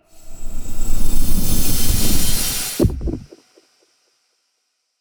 sacred-flame-caster-01.ogg